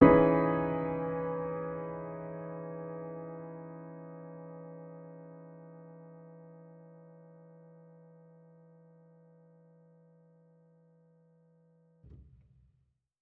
Index of /musicradar/jazz-keys-samples/Chord Hits/Acoustic Piano 2
JK_AcPiano2_Chord-Emaj13.wav